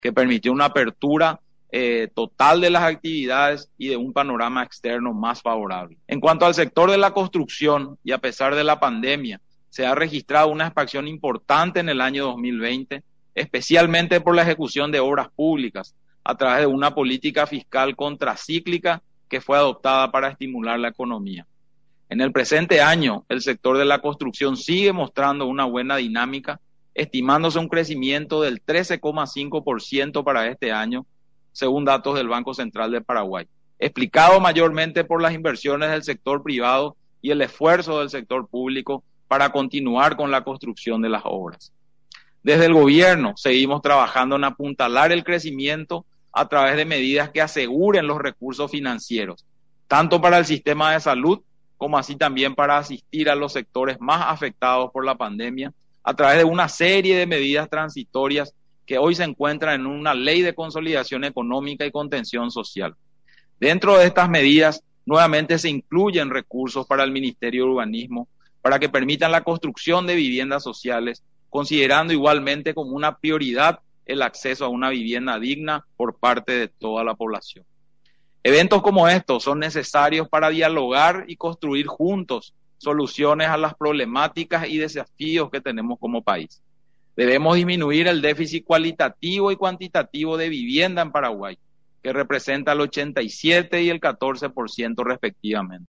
Con el repunte en el sector de la construcción, principalmente en la ejecución de las obras públicas a nivel país, se registrará un aumento del 13,5% durante este año, que ayudará al crecimiento en la economía, según estimaciones del Banco Central del Paraguay (BCP), resaltó este martes en declaraciones a Radio Nacional del Paraguay, el ministro de Hacienda, Oscar Llamosas.